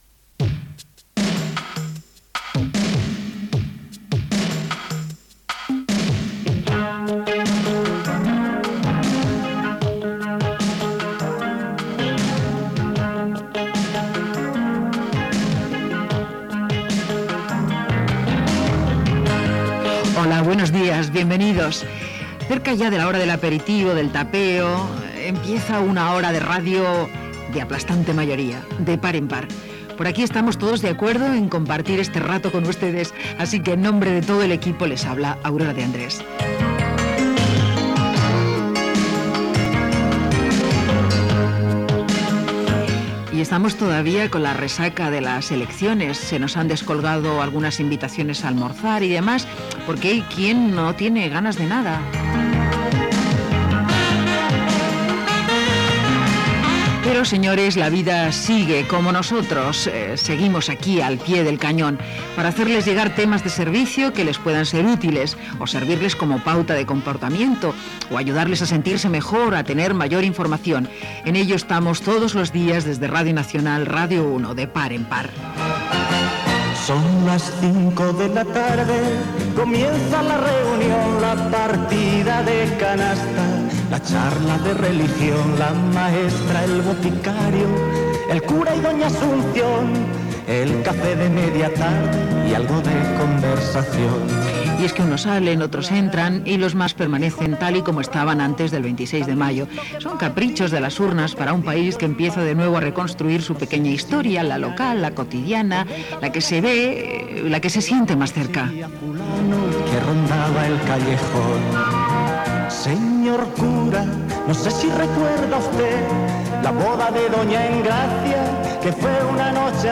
Sintonia, presentació, comentari sobre les eleccions polítiques, 30 anys de la fundació d'Amninstia Internacional, telèfon del programa, les belles malvades
Entreteniment